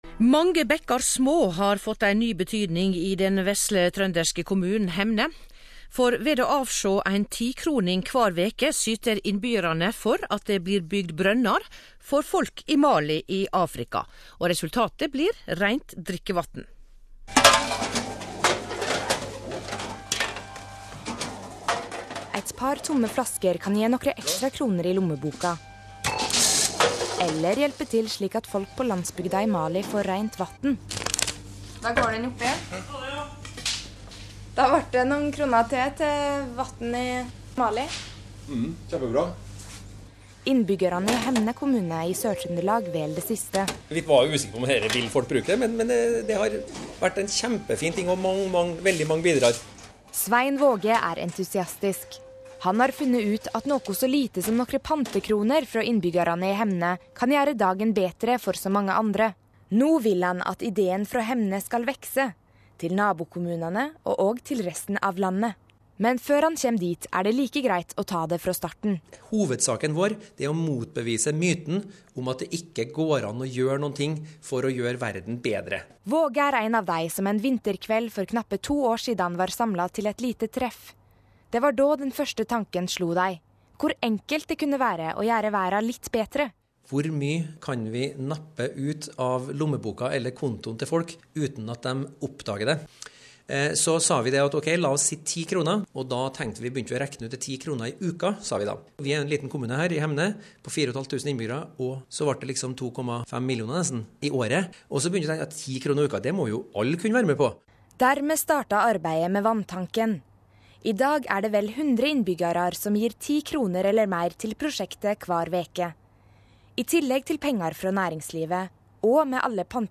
Radioprogram.
radioprogram.mp3